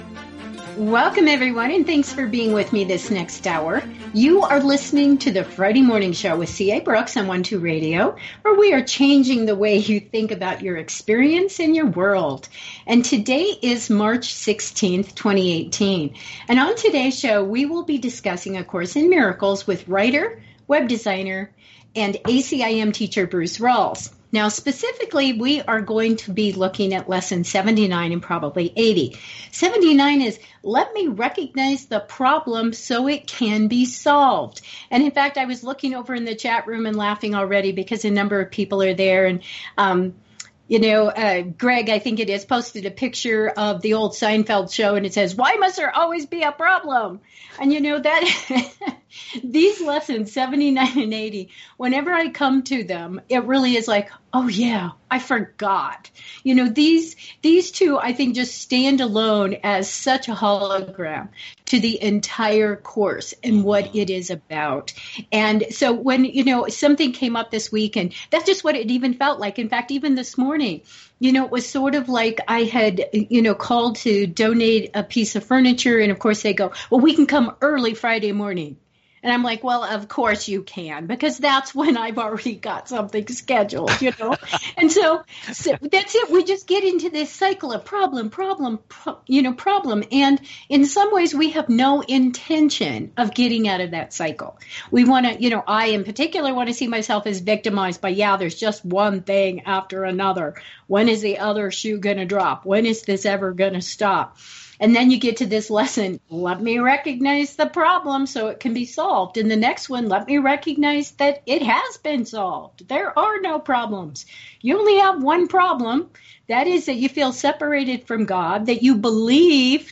audio conversation
weekly ACIM internet radio program